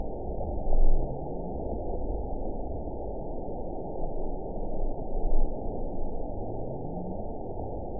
event 912361 date 03/25/22 time 13:34:11 GMT (3 years, 1 month ago) score 9.36 location TSS-AB02 detected by nrw target species NRW annotations +NRW Spectrogram: Frequency (kHz) vs. Time (s) audio not available .wav